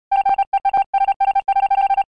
Among Us All Vote Out Typing Sound Effect Free Download
Among Us All Vote Out Typing